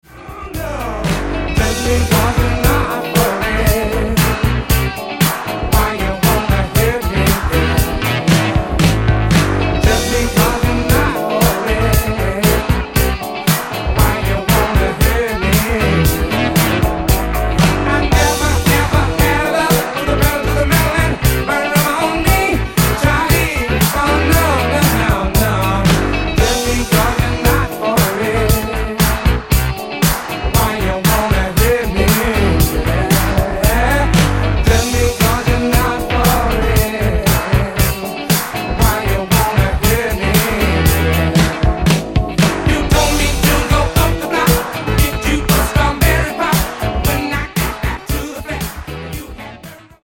Funky, Afro, Soul Groove e JazzFunk Anni 70 e 80.